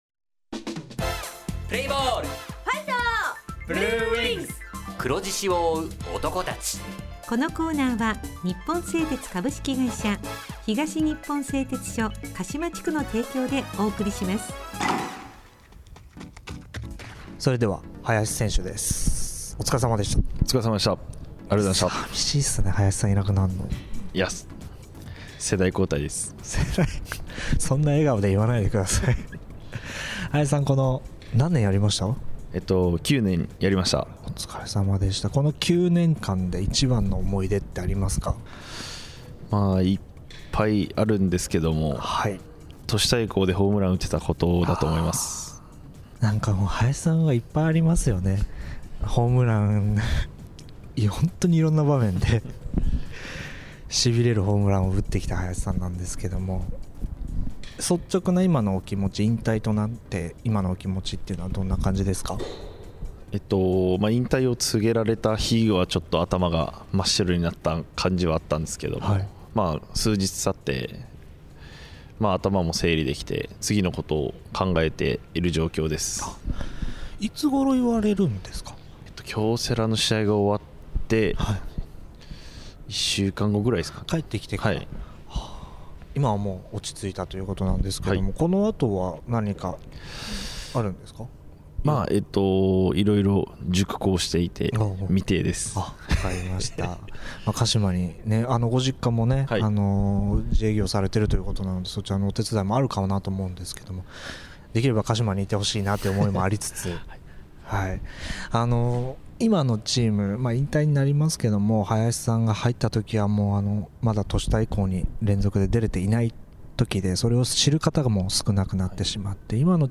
インタビュー
地元ＦＭ放送局「エフエムかしま」にて鹿島硬式野球部の番組放送しています。